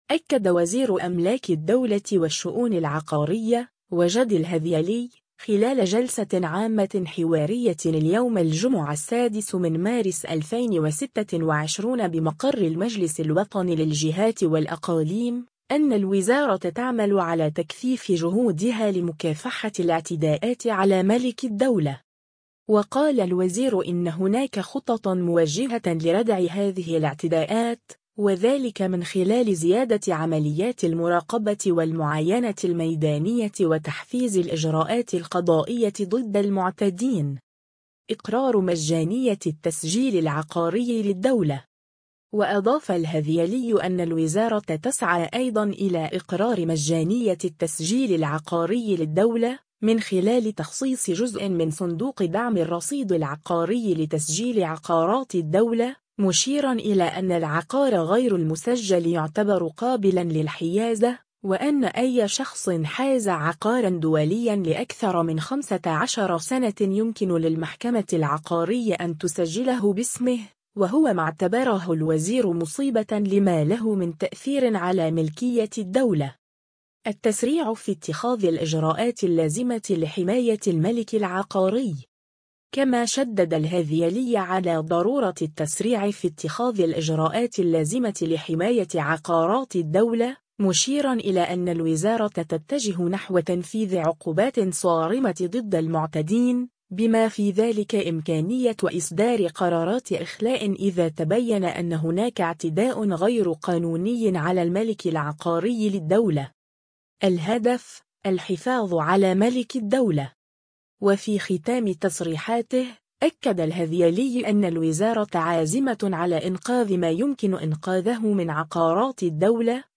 أكد وزير أملاك الدولة والشؤون العقارية، وجدي الهذيلي، خلال جلسة عامة حوارية اليوم الجمعة 6 مارس 2026 بمقر المجلس الوطني للجهات والأقاليم، أن الوزارة تعمل على تكثيف جهودها لمكافحة الاعتداءات على ملك الدولة.